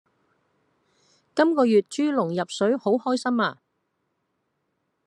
Голоса - Гонконгский 282